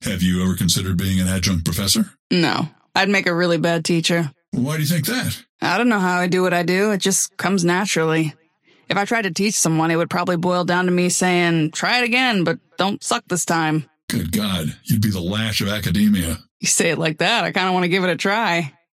Dynamo and McGinnis conversation 2